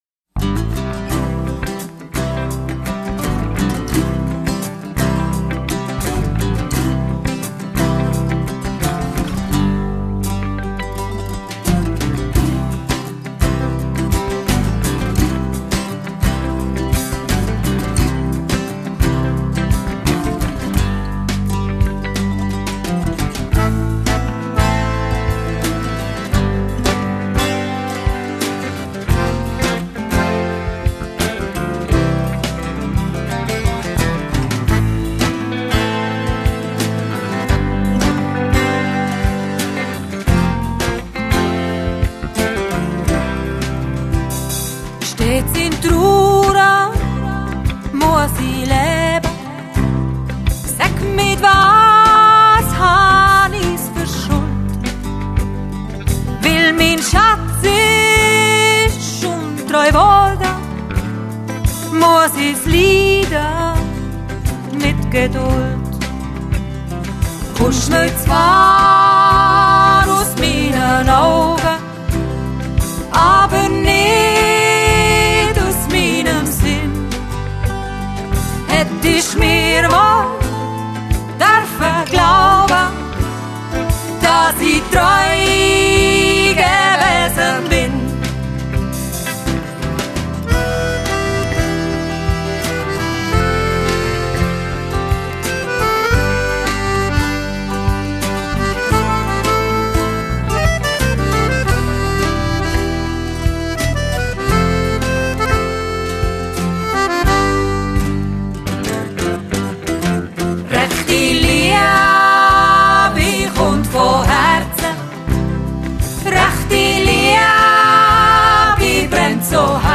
gitarre
schlagzeug
tönen wunderbar frisch und zeitlos   » weiter